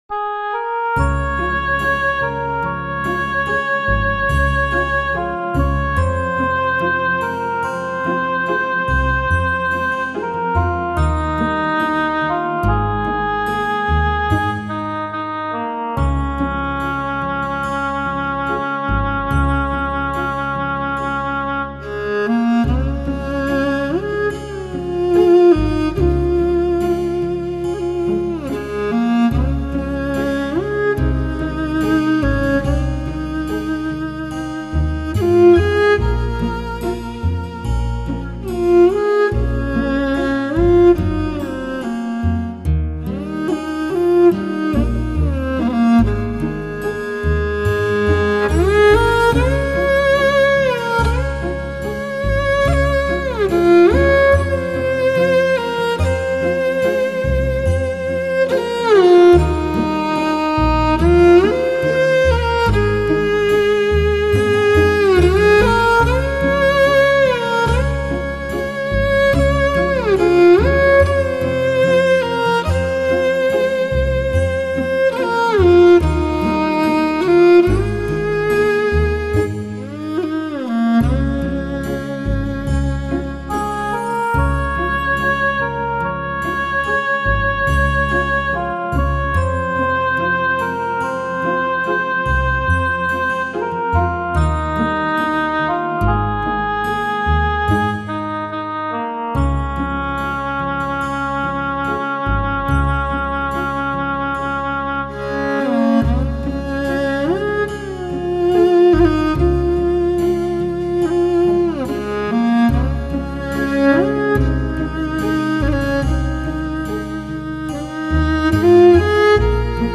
《马头琴》
《马头琴》中的音乐，广如蓝天，亮如银丝，飘逸如鸿雁的背影，苍劲如万马奔腾。